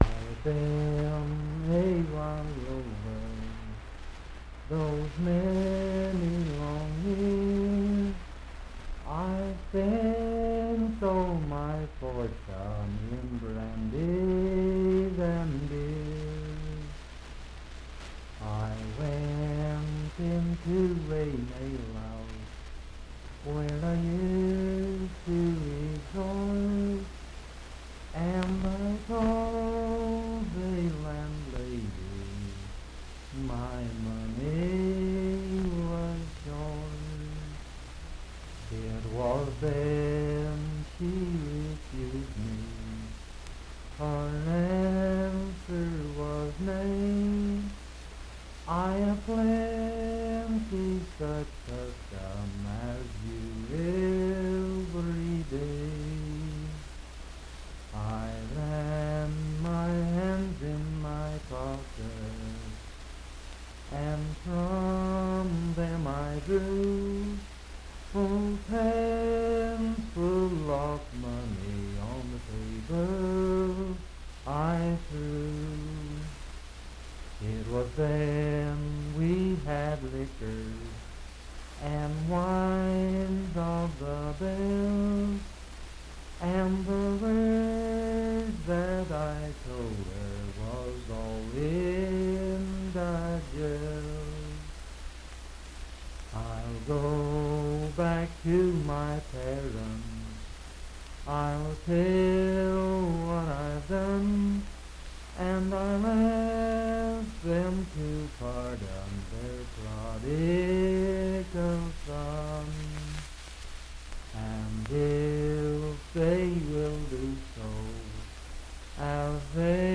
Unaccompanied vocal music
Voice (sung)
Pocahontas County (W. Va.), Marlinton (W. Va.)